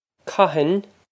cathain kah-hin
This is an approximate phonetic pronunciation of the phrase.